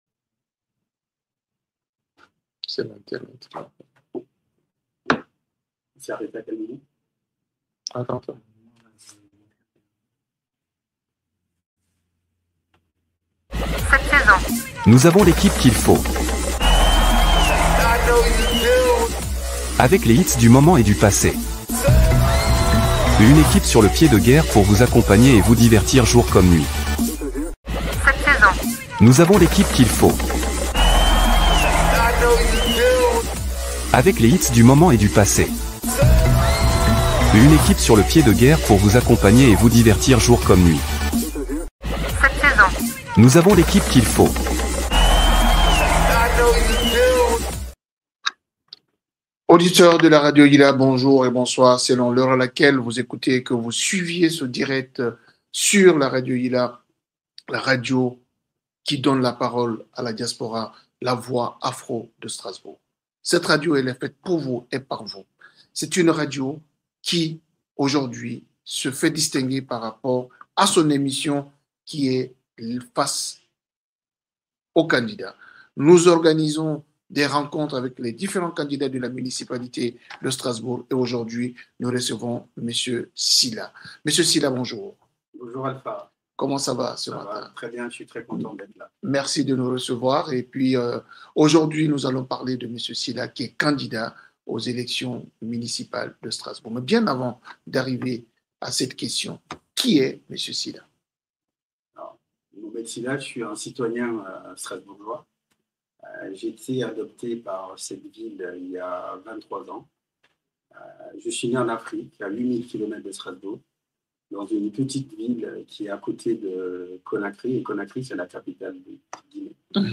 Dans le cadre de notre série consacrée aux élections municipales 2026, la deuxième émission spéciale s’est tenue sur les ondes de Radio Ylla – La Voix Afro de Strasbourg.
Au cours de cette émission, il a présenté : Son parcours et son engagement Sa vision pour Strasbourg Les priorités de son programme Et a répondu aux questions des auditeurs et des citoyens Cet échange direct, retransmis en live sur nos réseaux sociaux, a permis d’aborder les enjeux locaux : cadre de vie, sécurité, développement économique, services publics de proximité, jeunesse et cohésion sociale.